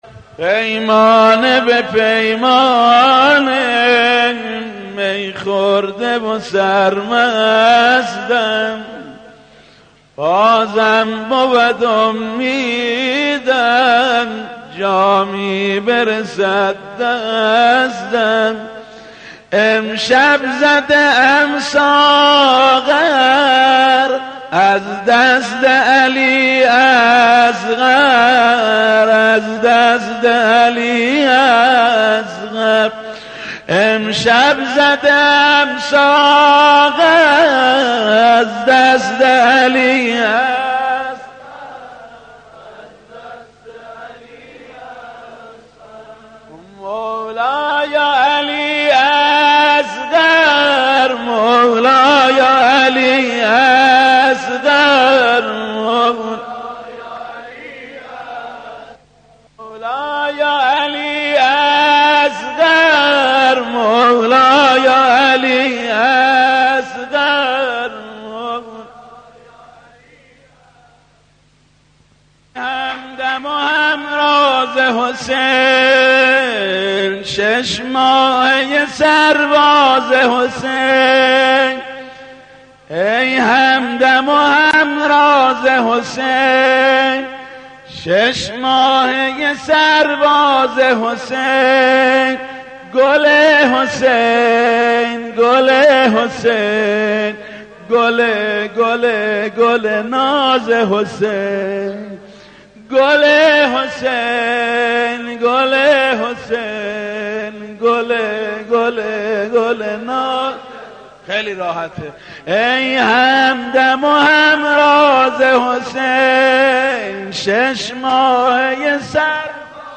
صوت/مداحی حاج محمود کریمی ولادت حضرت علی اصغر و امام جواد (ع) - تسنیم
خبرگزاری تسنیم: مداحی حاج محمود کریمی درولادت حضرت علی اصغر منتشر می‌‌شود.